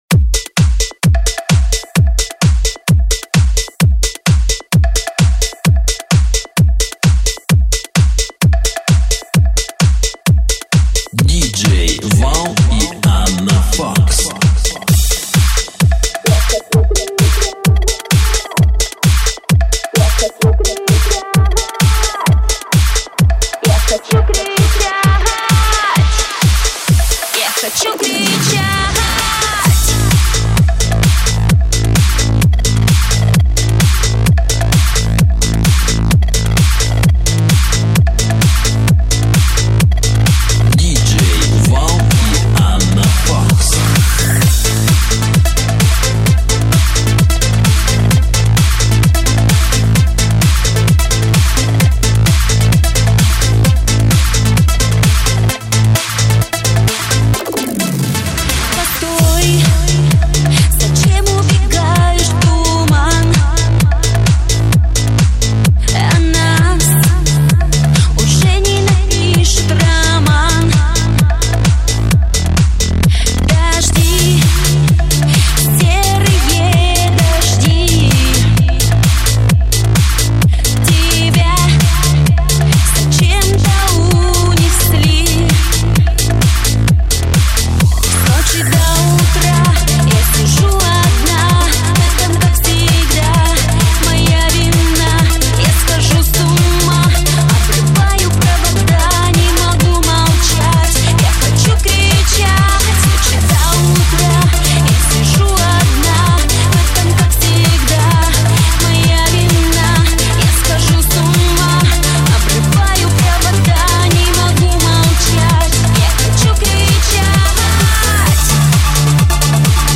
Стиль: Electro House / Electropop